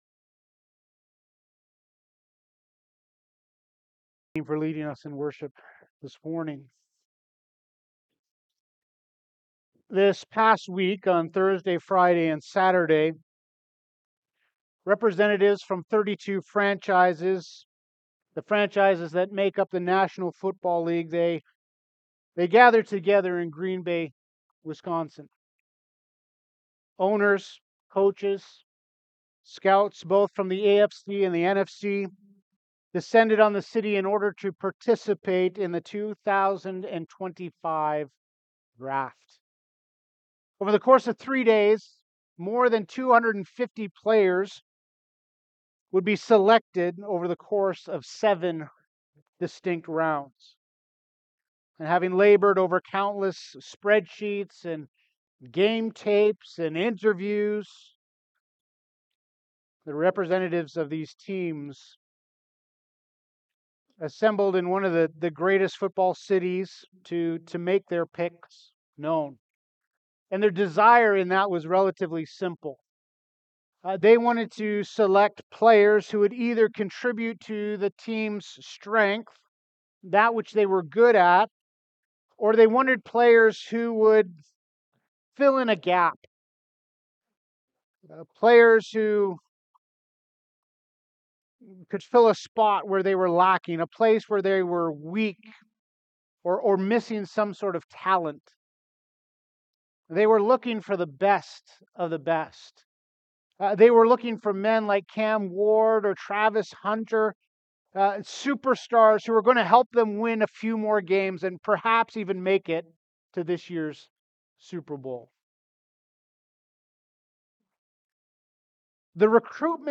Sermons - Grace Bible Fellowship